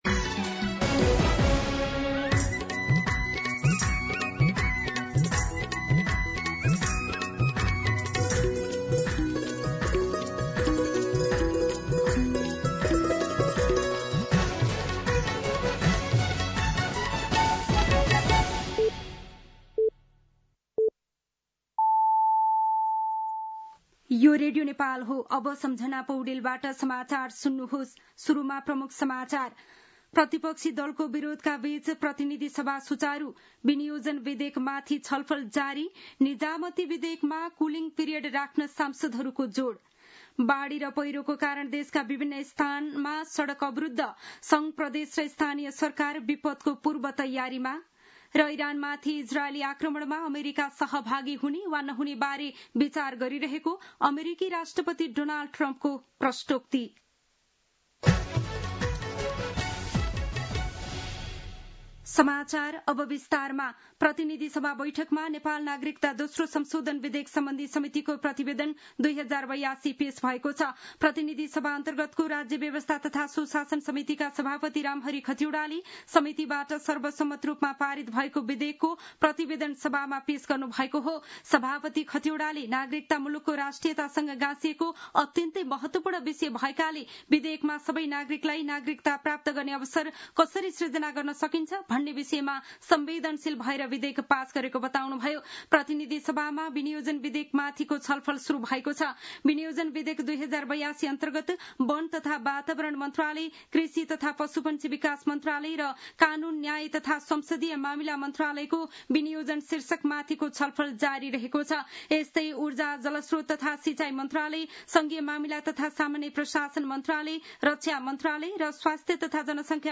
दिउँसो ३ बजेको नेपाली समाचार : ५ असार , २०८२
3-pm-Nepali-News-2.mp3